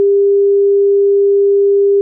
Tone_400Hz.ogg.mp3